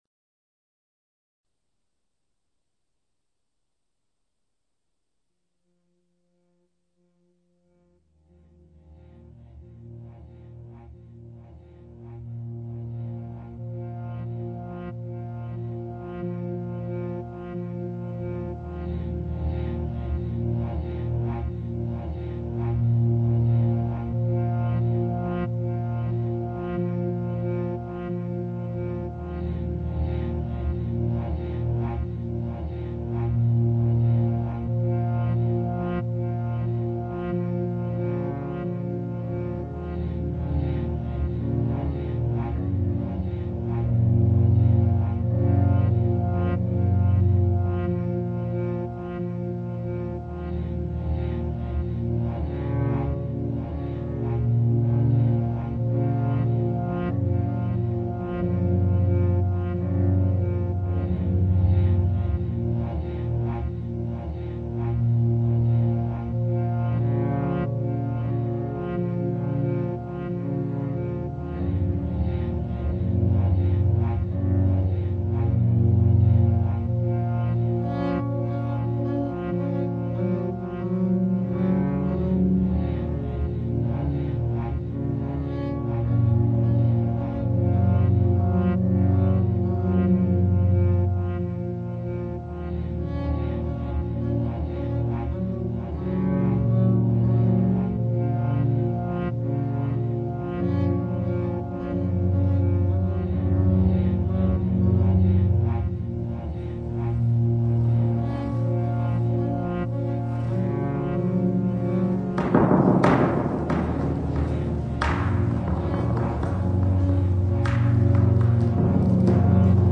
Field recordings and melodicas
Cello Textures